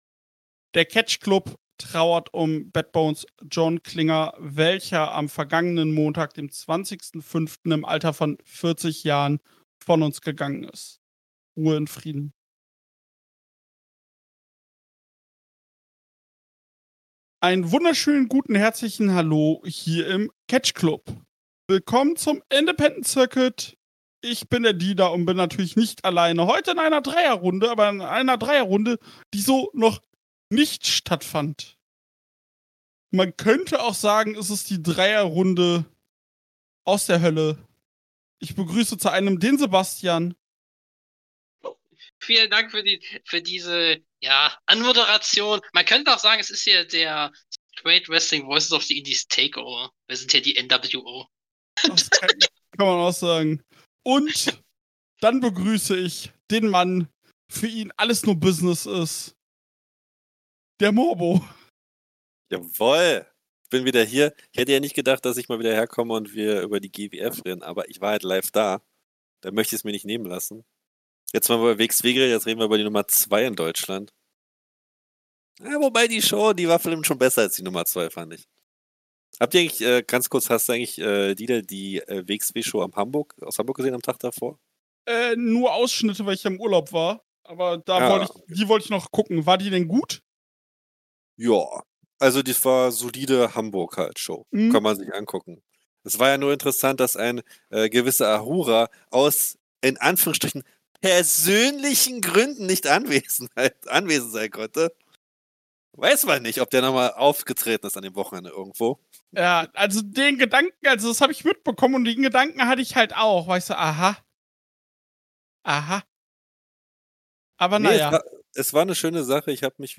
gehen sie zu dritt auf diese Show ein!